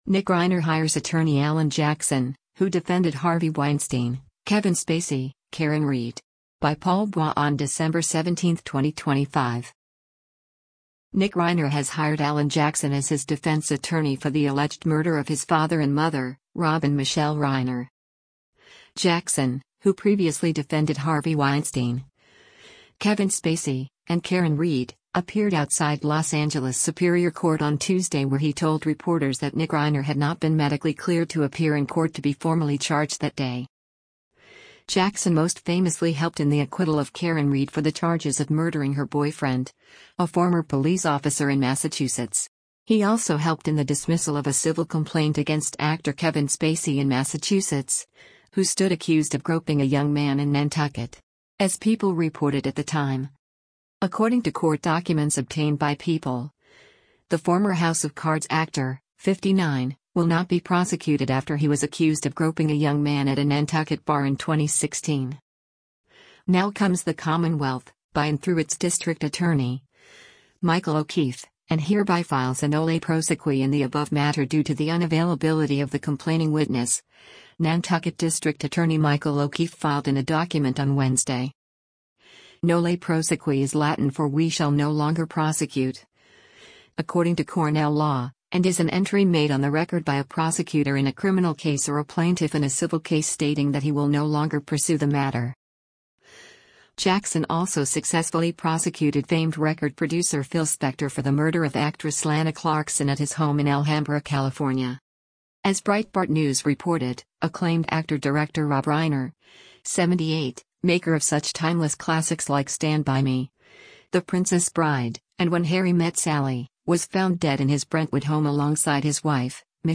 opening statement